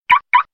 دانلود صدای آژیر قفل در ماشین از ساعد نیوز با لینک مستقیم و کیفیت بالا
جلوه های صوتی
برچسب: دانلود آهنگ های افکت صوتی حمل و نقل